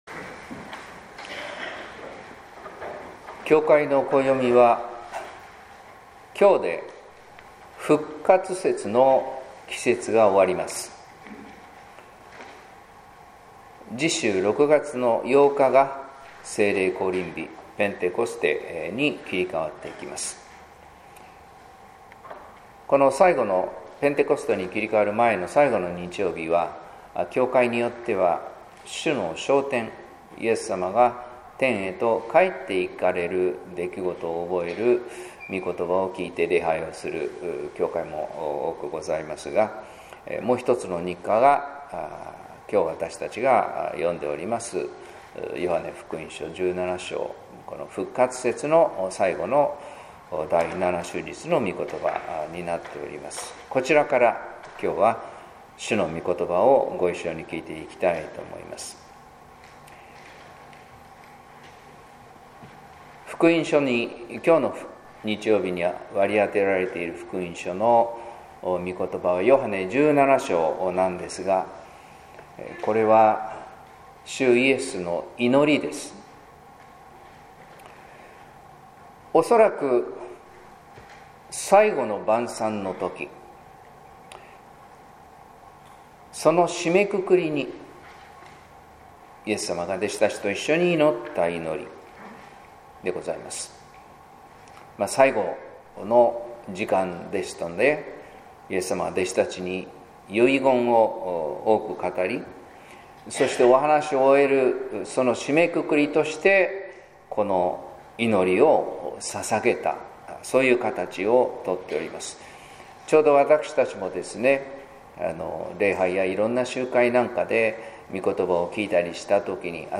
説教「神の愛の力」（音声版）